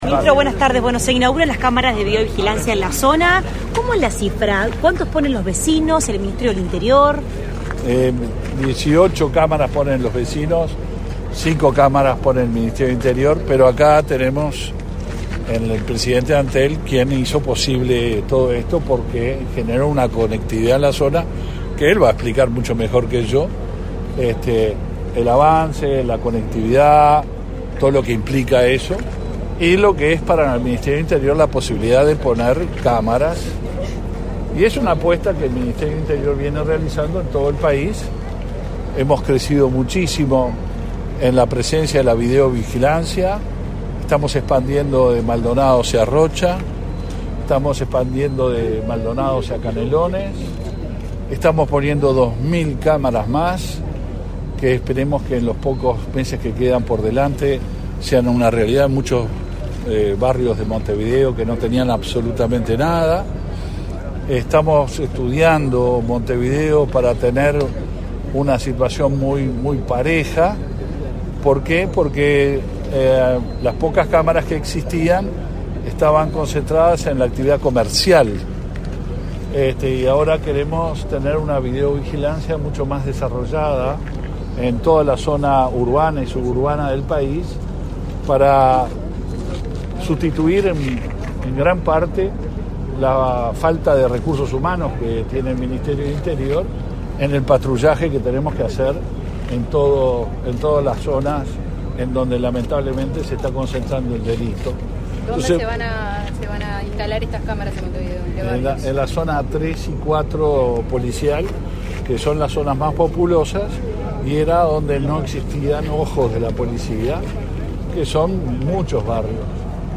Declaraciones a la prensa del ministro del Interior, Luis Alberto Heber
Tras el evento, el jerarca realizó declaraciones a la prensa.